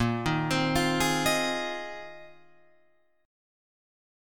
A#9sus4 chord